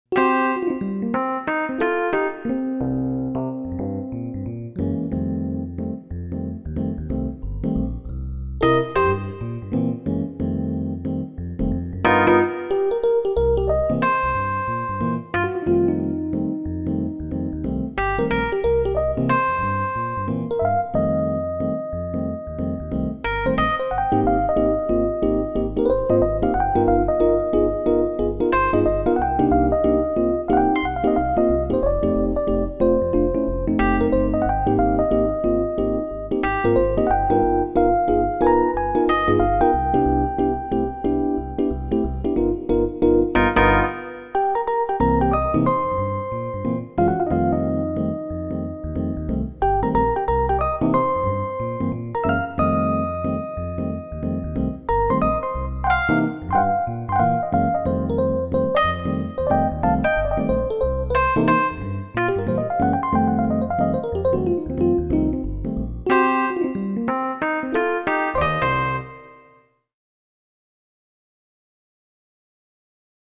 P80EPiano2.mp3